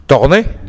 Tournai (/tʊərˈn/ toor-NAY, French: [tuʁnɛ] ; Picard: Tornai; Walloon: Tornè [tɔʀnɛ]
Wa_pcd_Tornè.ogg.mp3